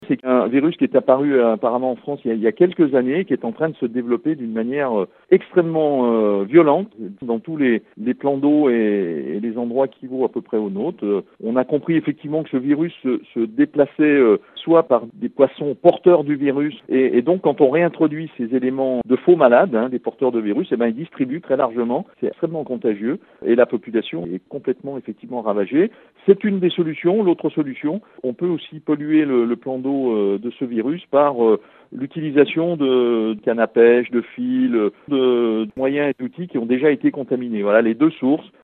La propagation serait liée à de multiples facteurs comme nous le confirme Christian Heison, le maire de Rumilly